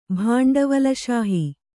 ♪ bhāṇḍavala śahi